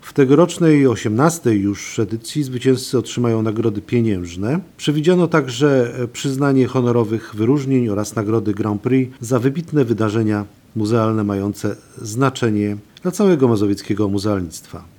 – Mazowieckie muzea mogą w ten sposób zaprezentować swoje działania, a dodatkowo otrzymać prestiżowe wyróżnienie. W tegorocznej 18 już edycji zwycięzcy otrzymają nagrody pieniężne. Przewidziane jest też przyznanie honorowych wyróżnień oraz nagrody Grand Prix za wybitne wydarzenia muzealne mające znaczenie dla całego mazowieckiego muzealnictwa – mówi wicemarszałek województwa Wiesław Raboszuk.